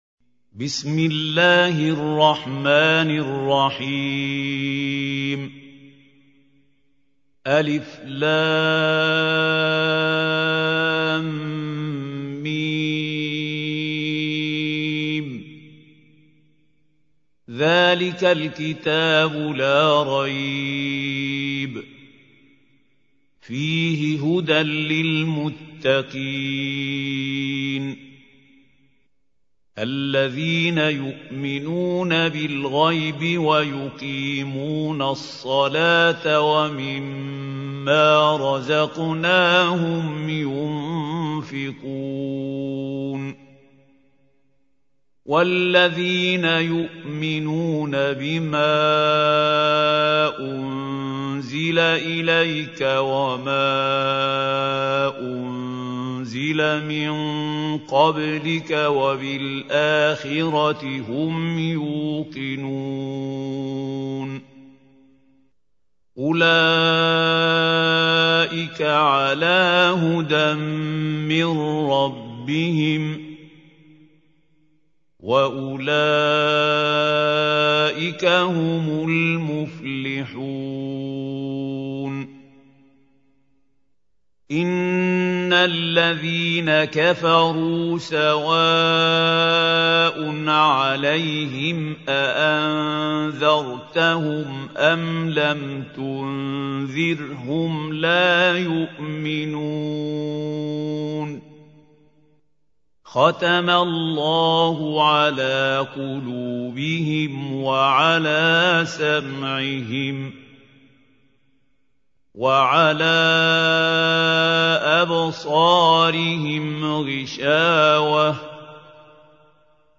Listen online and download beautiful recitation of Surah Al Baqarah (The Cow) in the voice Qari Mahmoud Khalil Al Hussary.